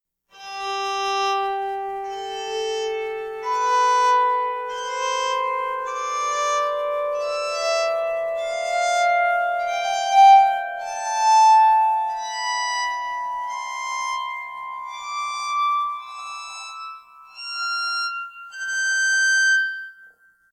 Let’s hear our project psaltery play all the naturals on the right-hand side, from G4 up to G6.
I played it alongside other psalteries, and the tone on this one is really deep and full.
scale.mp3